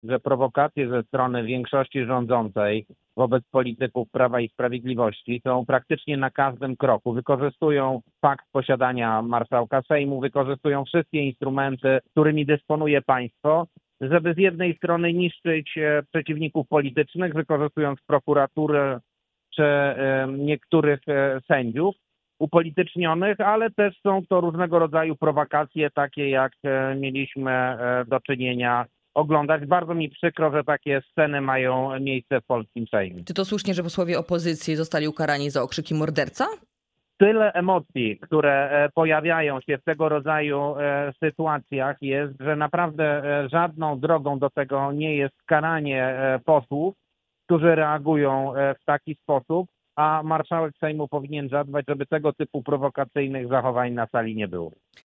Michał Dworczyk był gościem wrocławskiego radia „Rodzina”